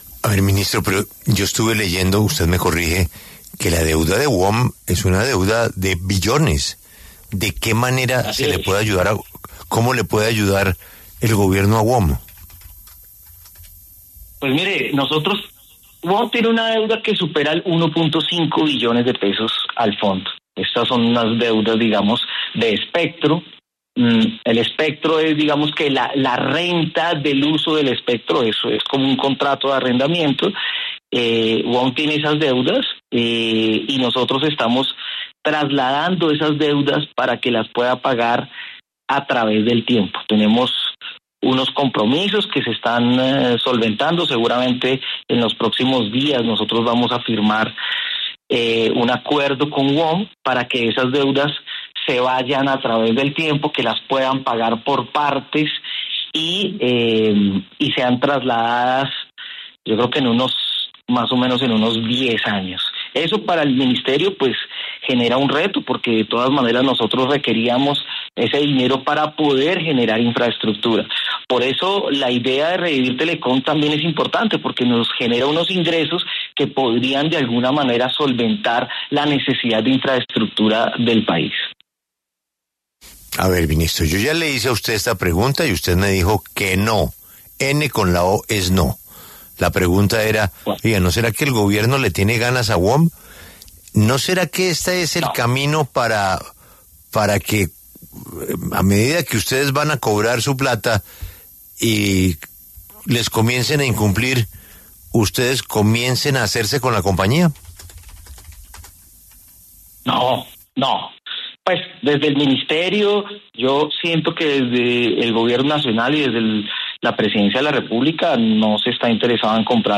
“WOM tiene una deuda que supera los 1,5 billones de pesos al fondo. Estamos trasladando esas deudas para que las puedan pagar a través del tiempo”, explicó el ministro de Tecnologías de la Información y las Comunicaciones, Julián Molina, en entrevista con La W.